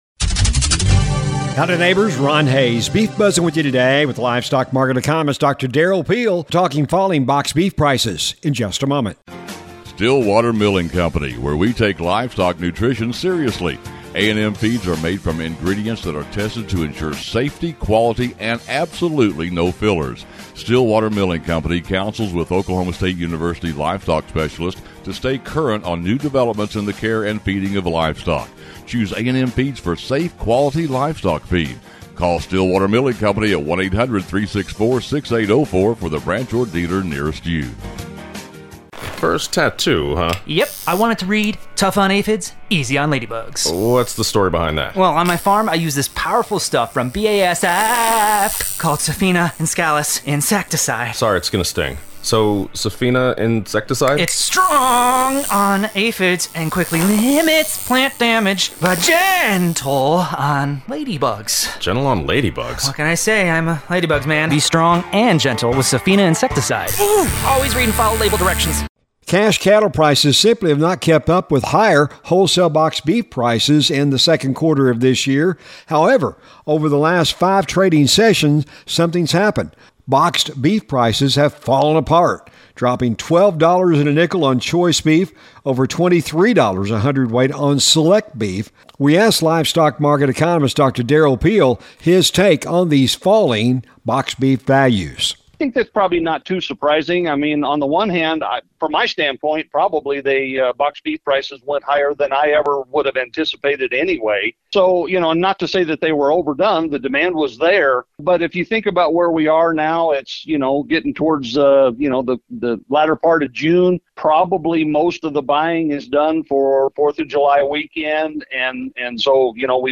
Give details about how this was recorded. The Beef Buzz is a regular feature heard on radio stations around the region on the Radio Oklahoma Network and is a regular audio feature found on this website as well.